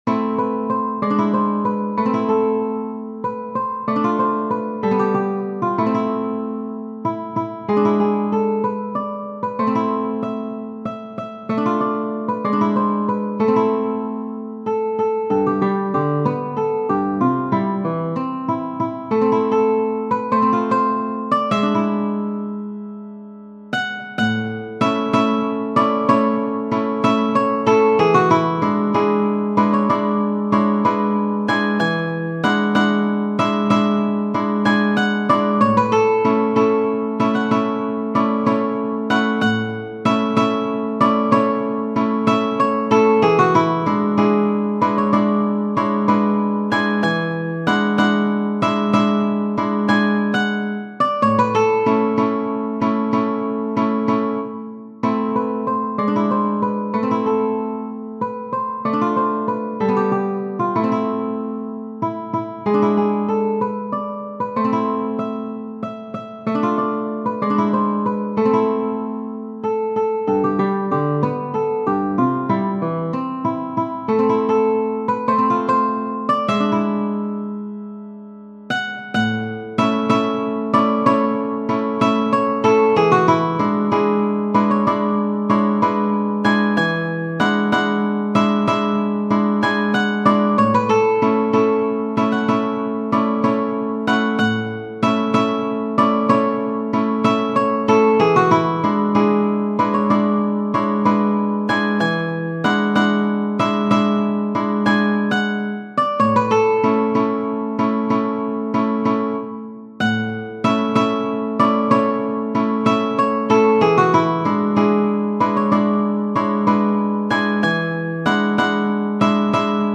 Genere: Folk